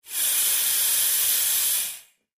Processed Air Release